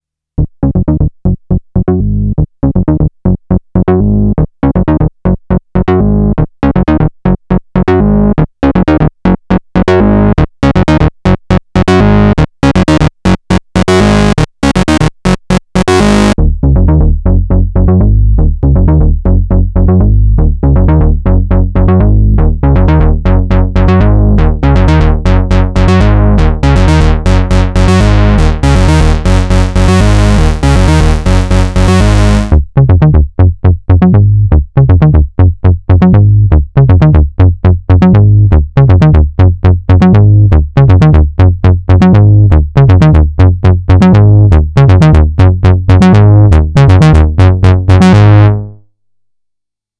Der SE02 hat einen wunderbaren Sound, besonders bei geschlossen Filter. Da wird es schön grooveeee.
SE02 (Sync OSC12) - SE3X (Sync OSC123, Roland Filter) - CODE Luxe (Sync OSC12, Roland Filter): Anhang anzeigen SE02_SE3X_CODE_BASSSEQ_COMP1.wav